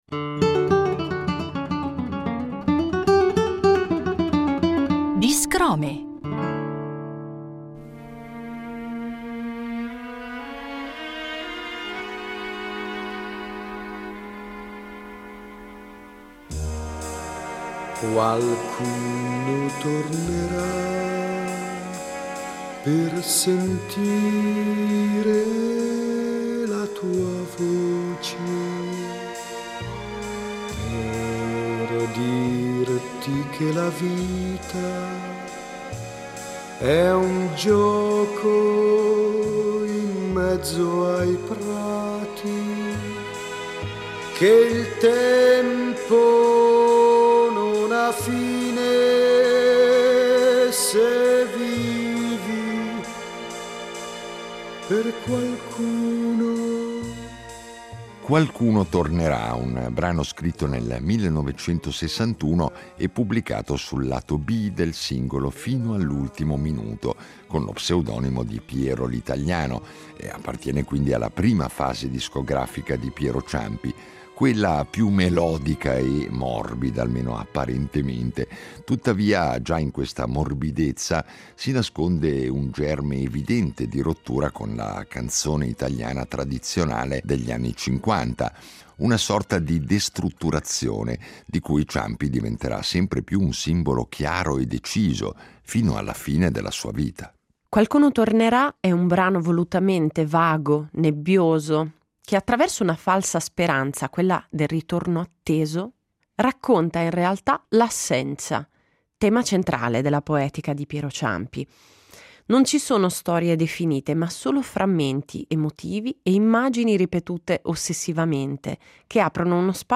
Ogni puntata di Non siamo tutti eroi segue una precisa drammaturgia radiofonica.
L’apertura propone un frammento originale delle storiche incisioni di Piero Ciampi, come gesto di memoria e invocazione d’archivio.
In chiusura, l’ascolto integrale della rilettura musicale tratta dal disco Non siamo tutti eroi, interpretata da un ensemble interamente femminile.